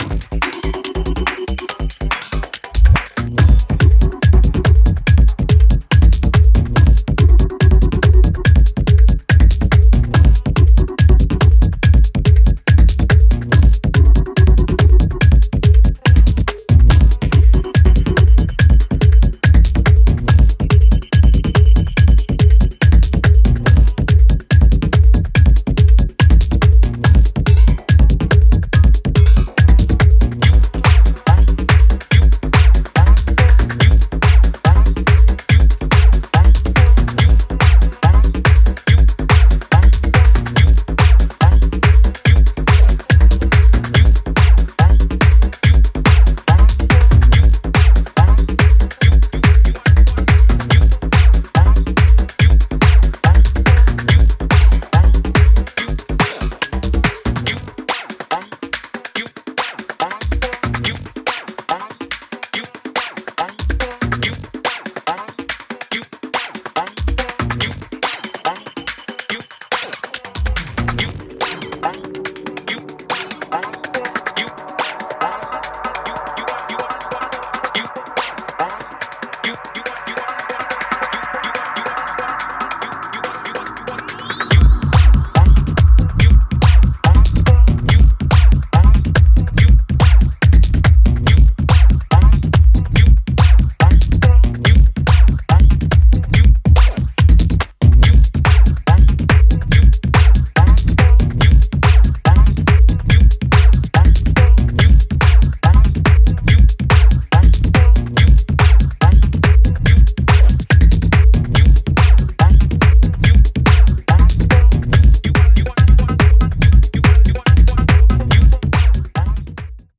全編非常に歯切れの良いハイテンポのグルーヴが走るモダン・テック・ハウスで痛快そのもの。